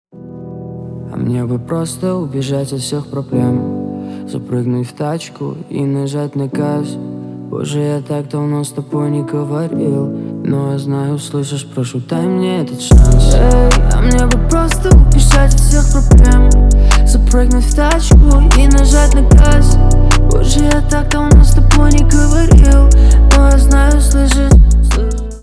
Поп Музыка
спокойные